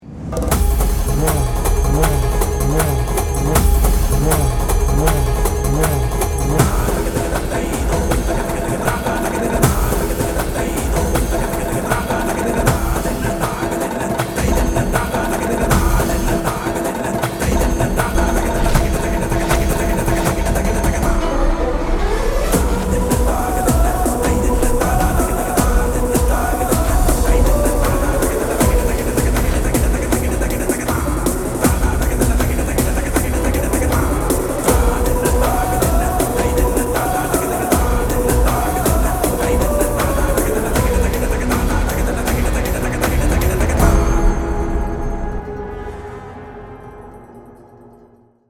without disturbing sounds and dialogues